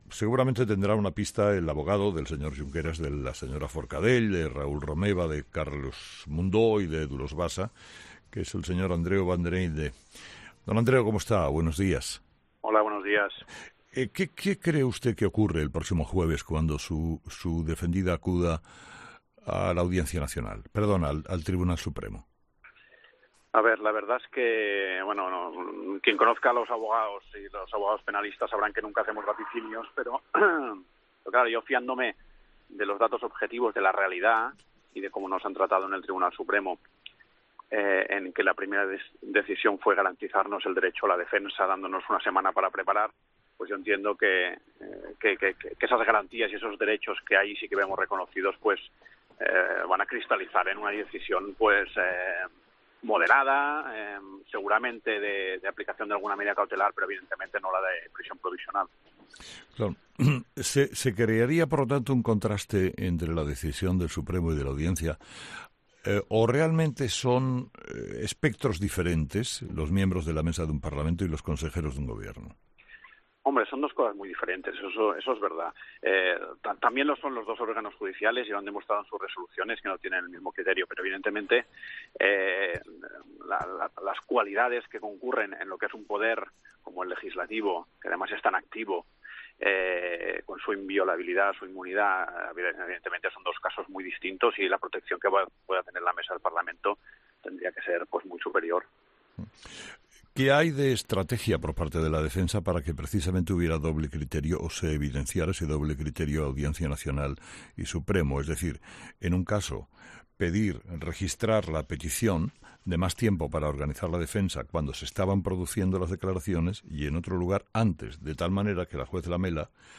Monólogo de las 8 de Herrera
Las posibles coaliciones tras las elecciones catalanas, en el editorial de Carlos Herrera